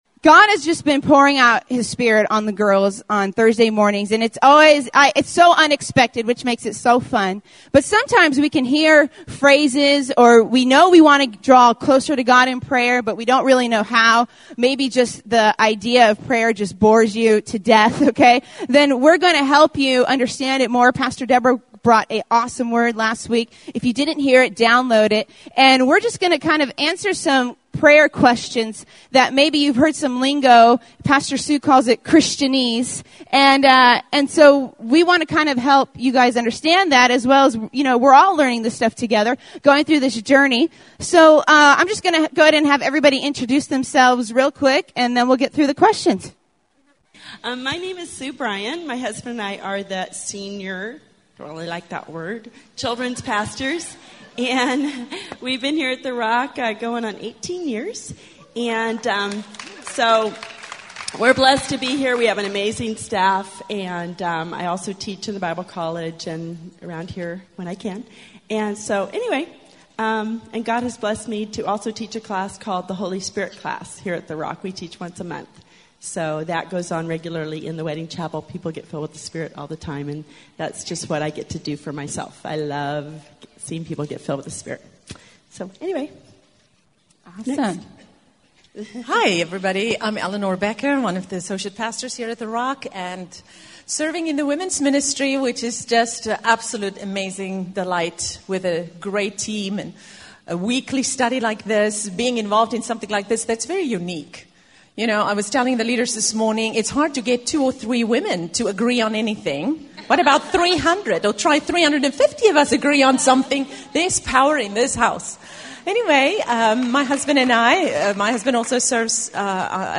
Panel_3_1_12_Girlfriends.mp3